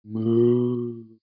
moowav.mp3